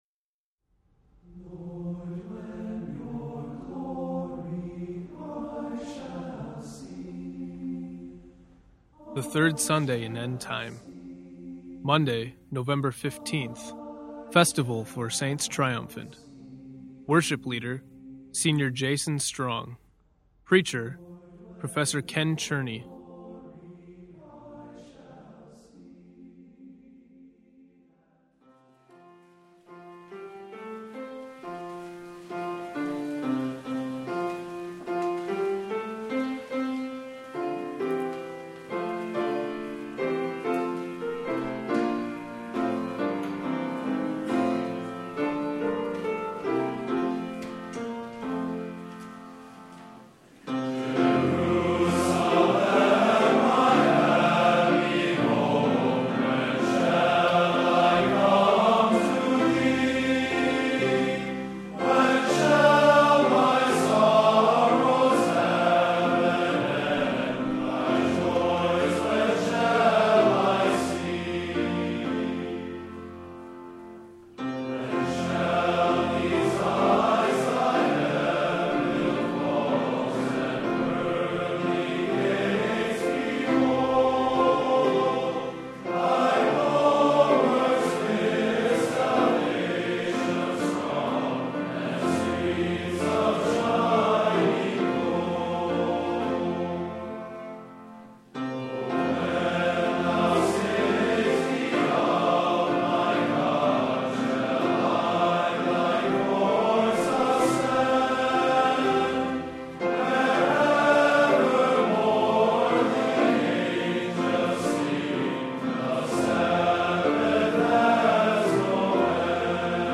Seminary Chapel 2010 Saints Triumphant Service